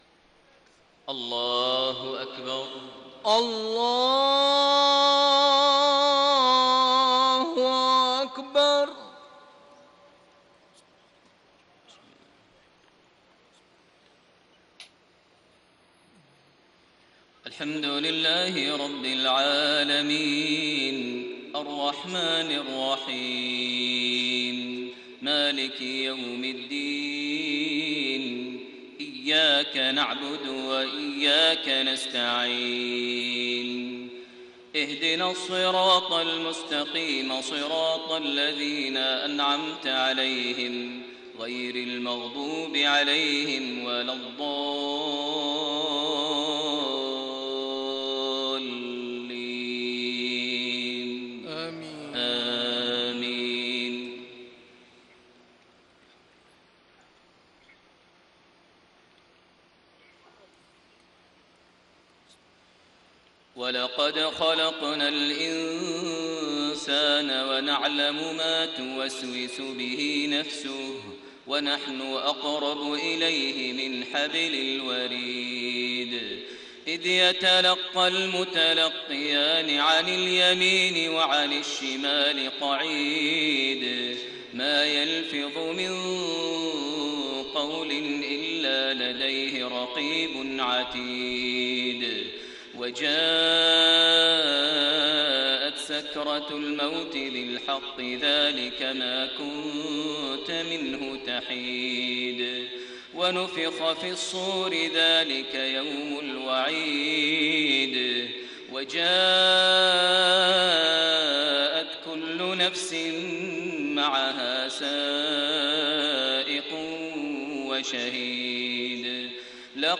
Maghrib prayer from Surah Qaf > 1433 H > Prayers - Maher Almuaiqly Recitations